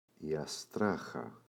αστράχα, η [a’straxa]